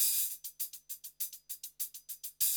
HIHAT LOP5.wav